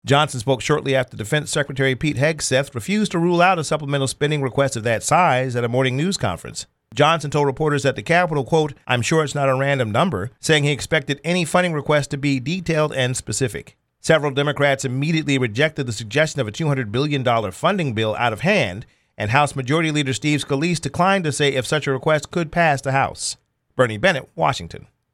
Johnson told reporters at the Capitol, “I’m sure it’s not a random number,” saying he expected any funding request to be “detailed and specified.”